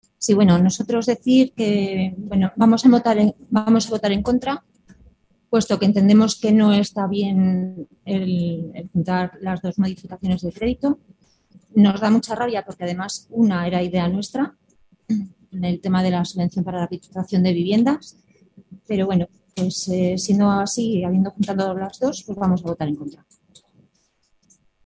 PLENO-1_.mp3